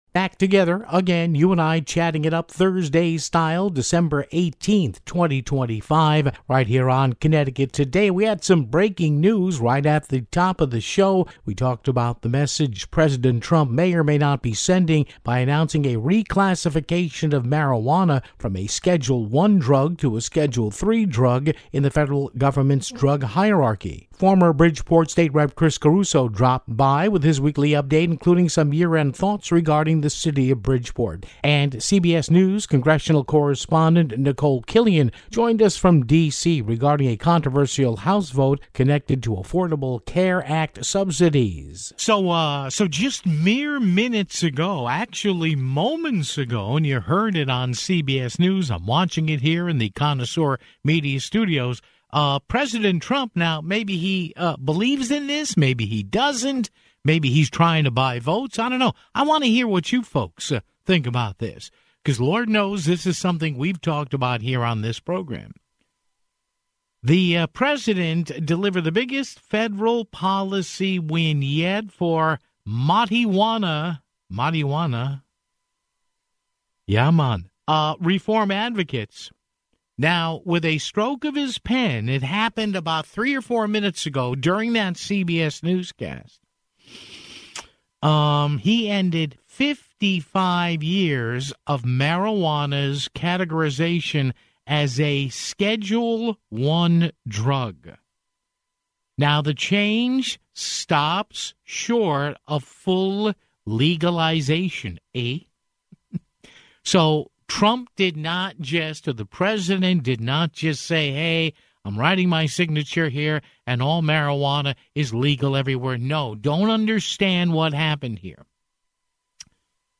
Former Bridgeport Democratic state Rep. Chris Caruso dropped by with his weekly update, including some year-end thoughts regarding the City of Bridgeport (15:09). CBS News Congressional Correspondent Nikole Killion joined us from Washington, D.C. regarding a controversial House vote connected to Affordable Care Act subsidies (23:04)